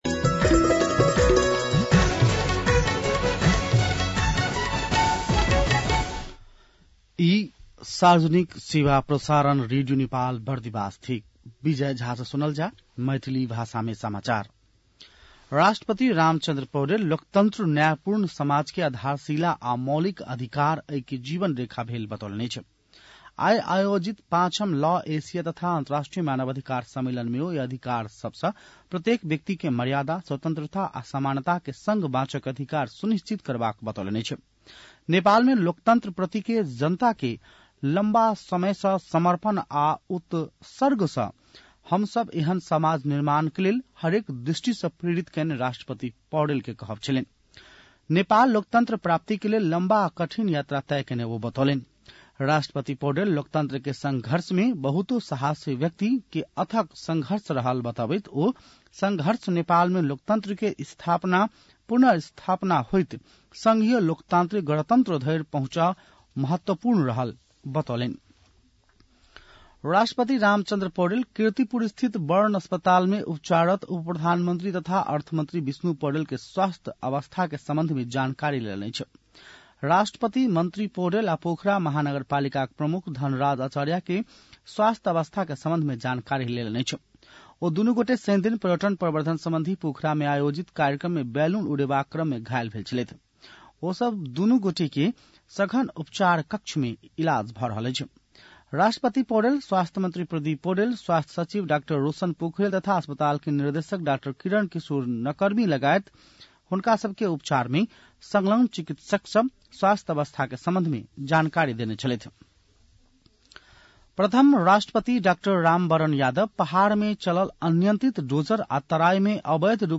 मैथिली भाषामा समाचार : ५ फागुन , २०८१